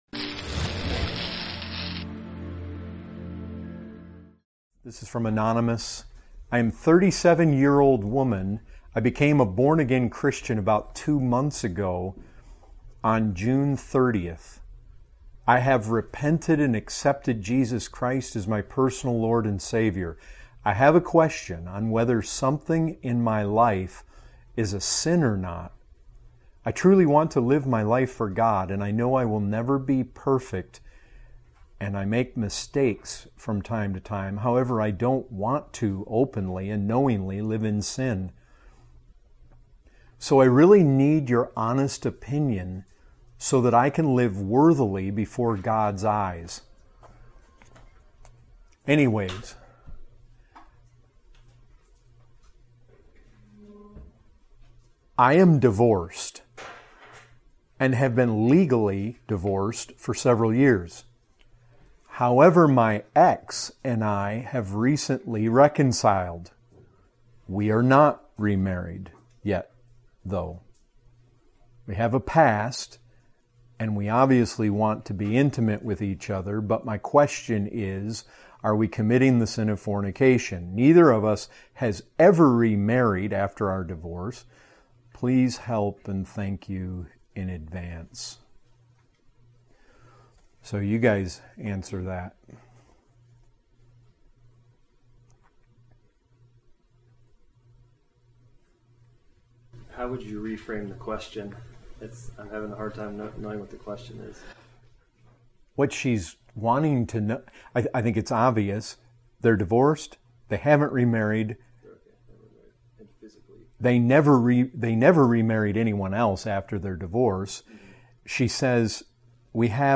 Category: Questions & Answers